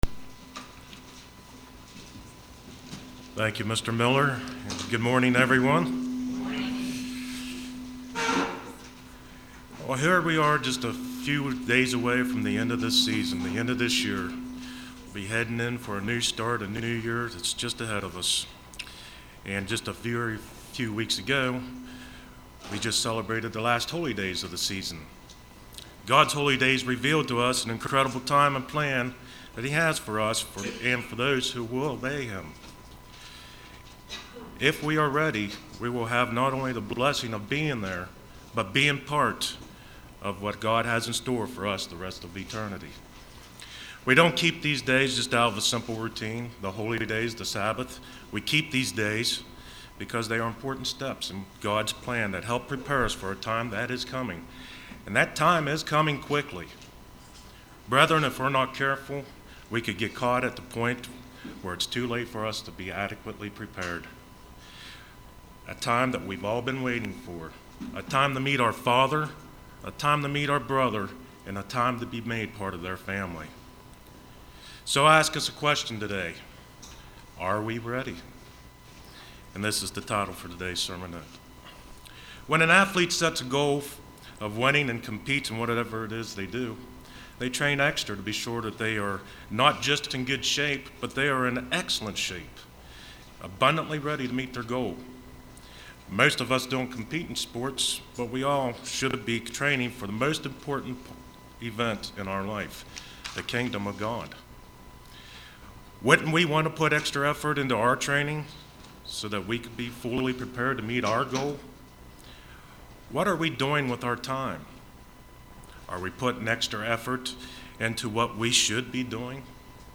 Print Are We Ready UCG Sermon Studying the bible?